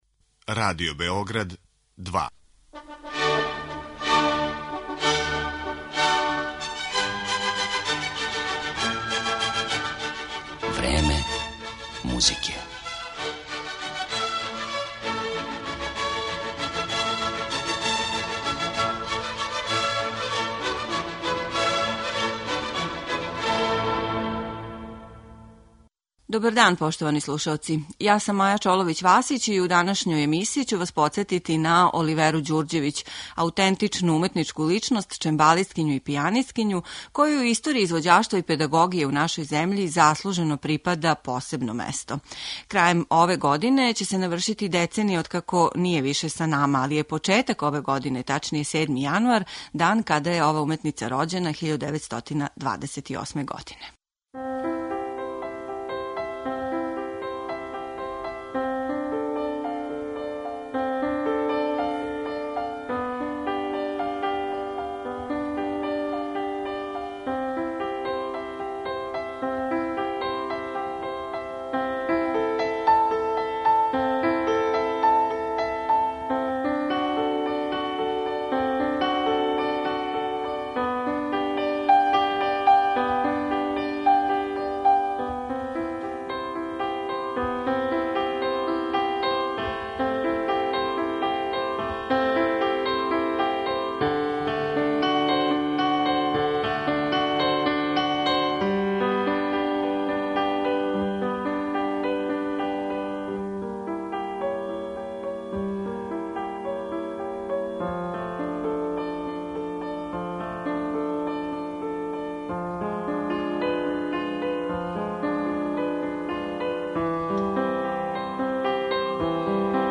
Скоро шест деценија је учествовала у креирању наше музичке сцене као солиста, камерни музичар и педагог, али је пре свега била синоним за извођача на чембалу.